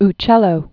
Uc·cel·lo